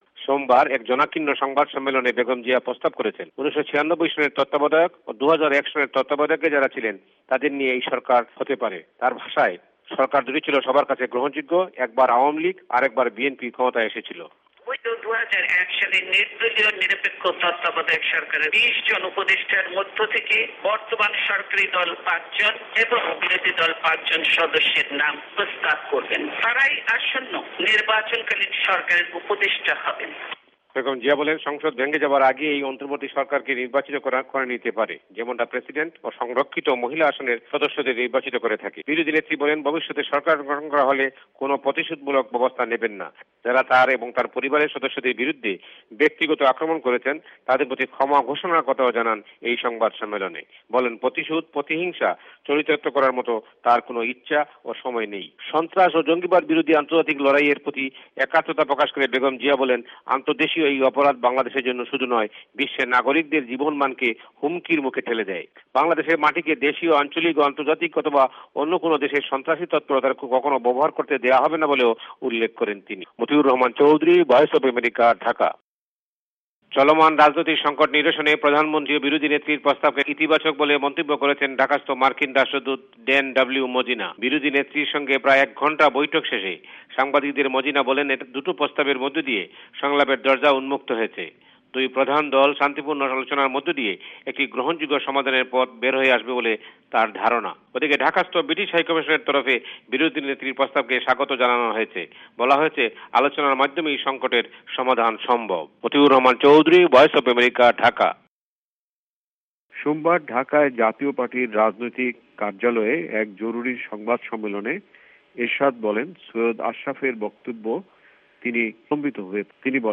বাংলাদেশে নির্বাচন-পূর্ব সরকার ব্যবস্থা নিয়ে পাল্টাপাল্টি প্রস্তাব- ভয়েস অফ এ্যামেরিকার রিপোর্ট